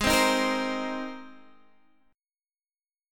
Abadd9 chord